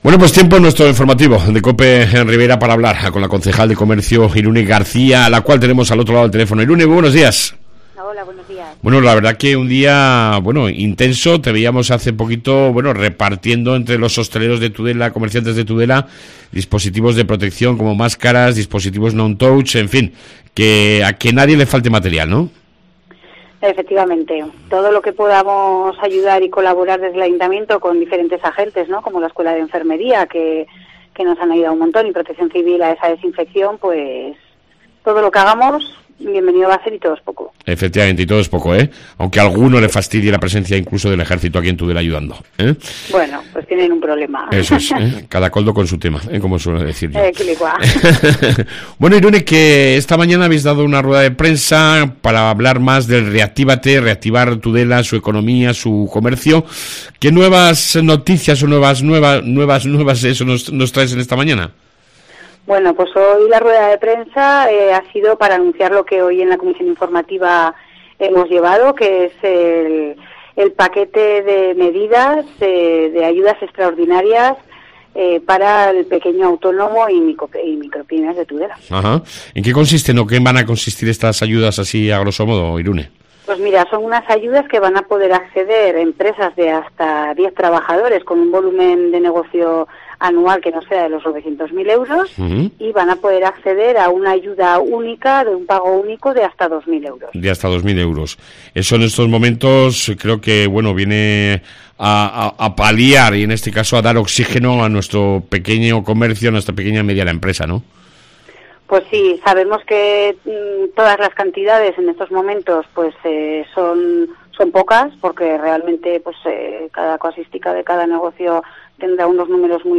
AUDIO: Entrevistamos a la concejal de Comercio Irune García